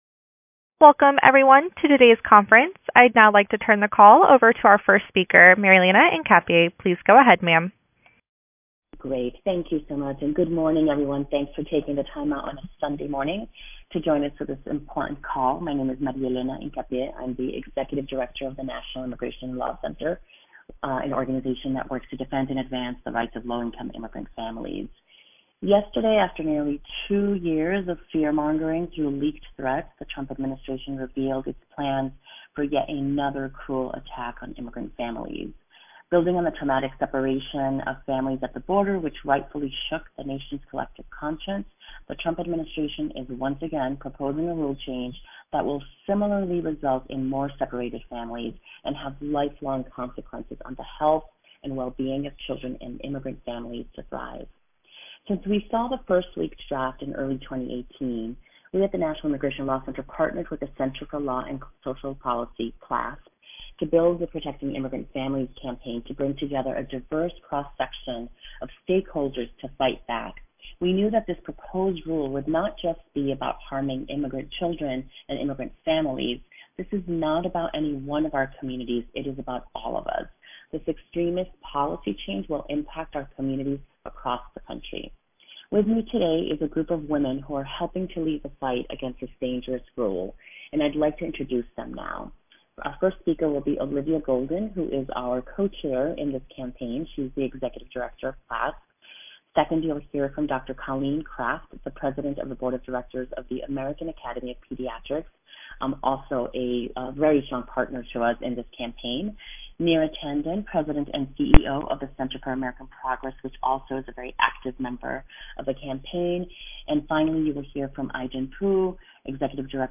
Recording of news teleconference about the Trump administration’s proposed rule on “public charge” (Sun., September 23, 2018)